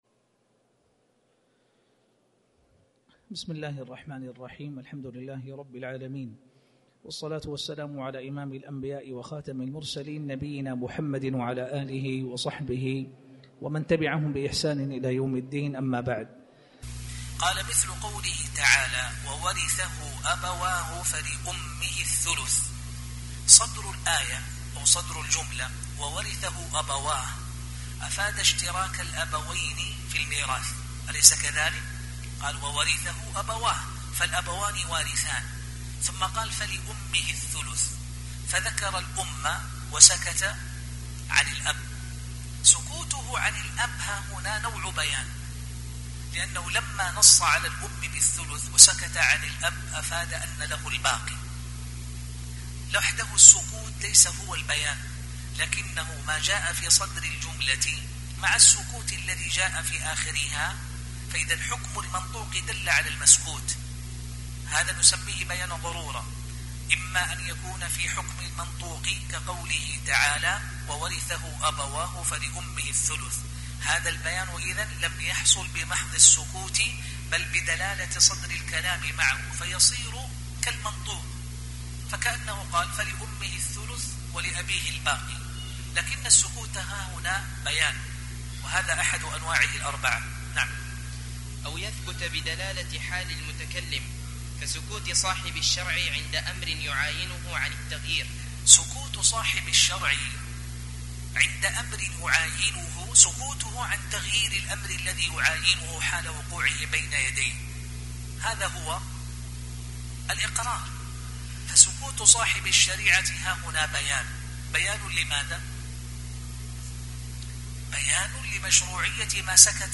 تاريخ النشر ٦ ربيع الأول ١٤٤٠ هـ المكان: المسجد الحرام الشيخ